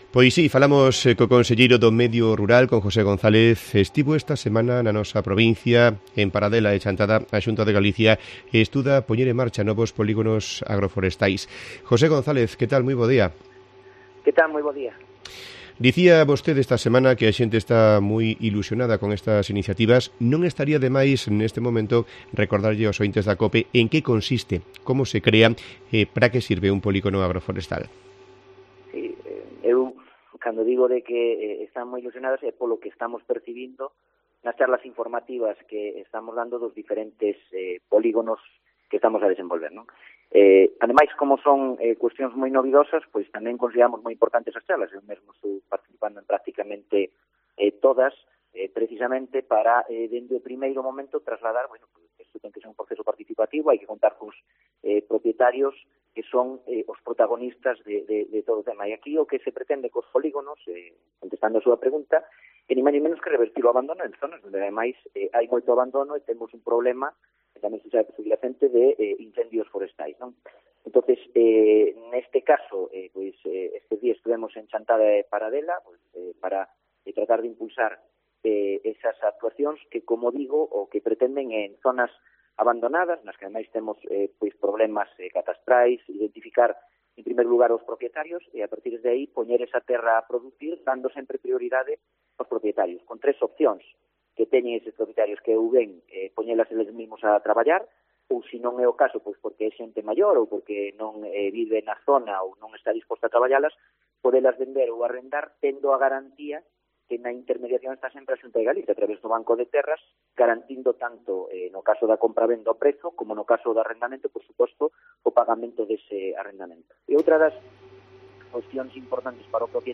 Entrevistamos al conselleiro de Medio Rural, José González
El conselleiro de Medio Rural, José González, se pasó este viernes por los micrófonos de Cope Lugo para hablar de los polígonos agroforestales que está desarrollando la Xunta de Galicia en la provincia, así como de los proyectos para crear aldeas modelo.